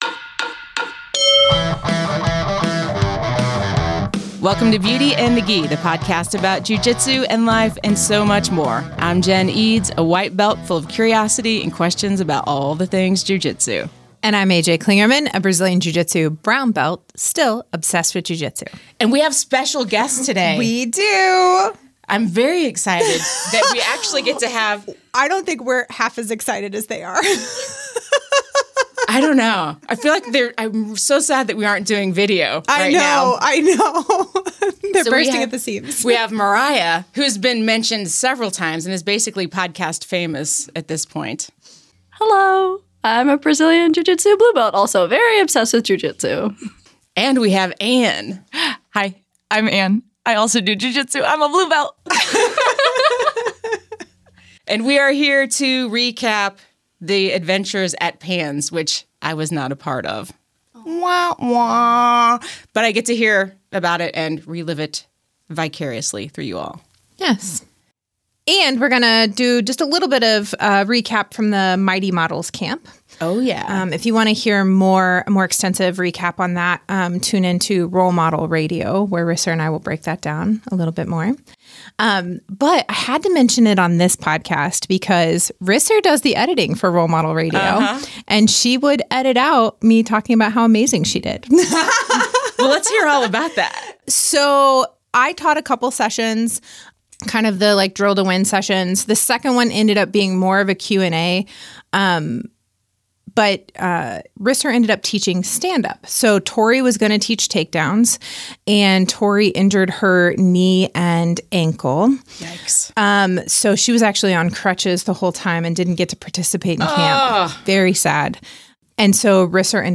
We’ve got a couple of special guests on the episode!